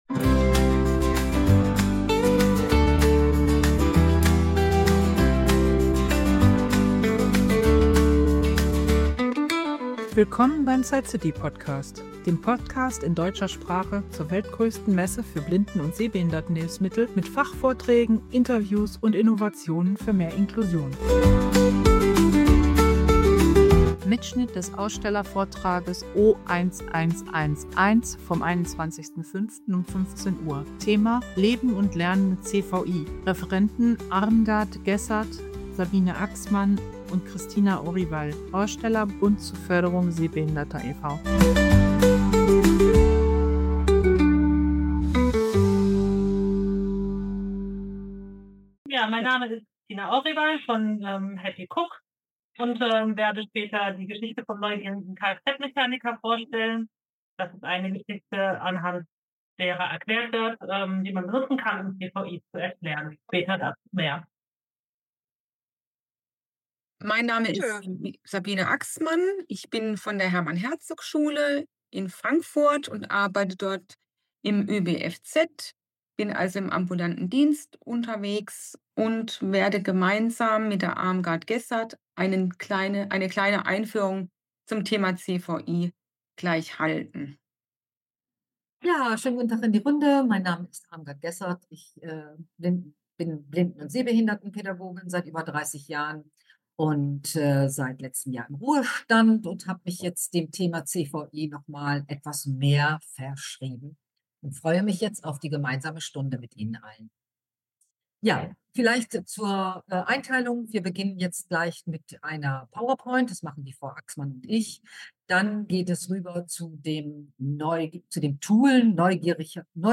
Die Expertinnen erklären die Grundlagen dieser oft übersehenen Sehbeeinträchtigung, bei der nicht die Augen, sondern die Verarbeitung der visuellen Informationen im Gehirn gestört ist. Besonders wertvoll sind die persönlichen Erfahrungsberichte zweier Teenagerinnen, die mit CVI leben und ihre Perspektiven teilen. Die Folge bietet wertvolle Einblicke für Fachpersonal, Eltern und Betroffene und stellt praktische Hilfsmittel wie die "CVI-Inspektionsliste" vor.